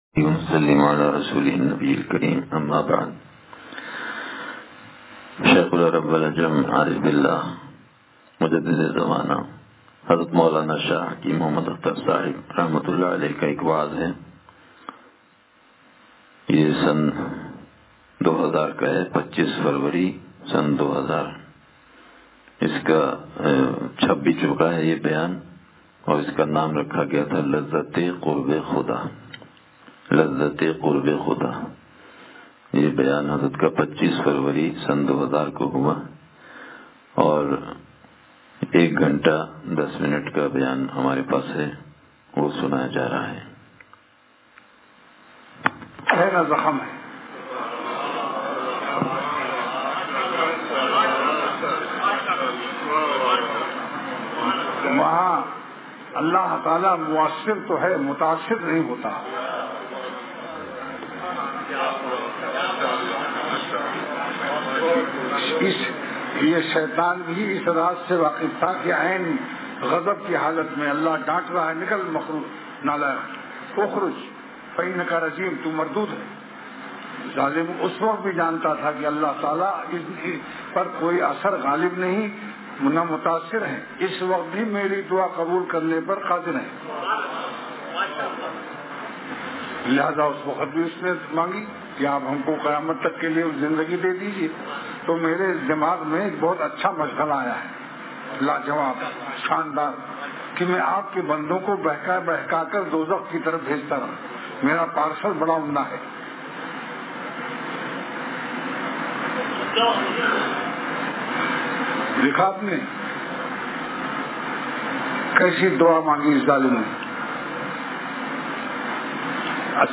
حضرت والا رحمتہ اللہ علیہ کا بیان لذت قربِ خدا